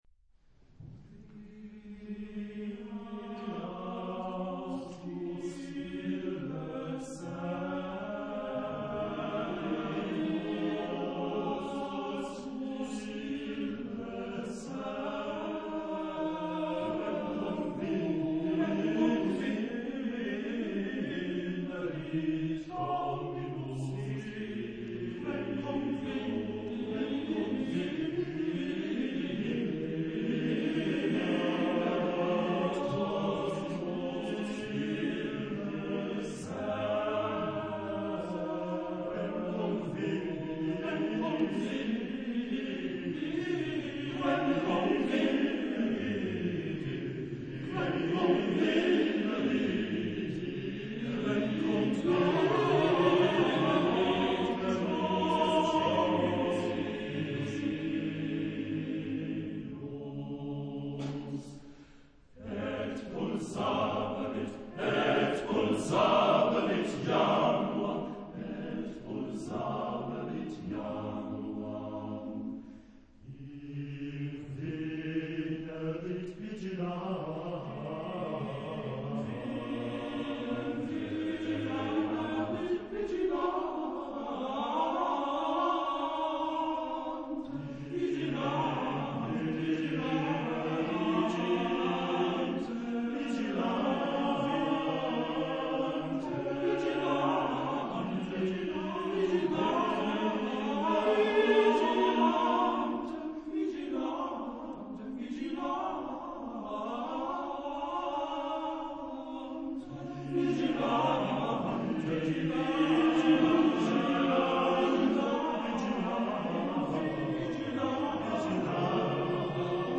Genre-Style-Forme : Baroque ; Sacré ; Motet
Type de choeur : TTBB  (4 voix égales d'hommes )
Instruments : Basse continue (ad lib)
Réf. discographique : Internationaler Kammerchor Wettbewerb Marktoberdorf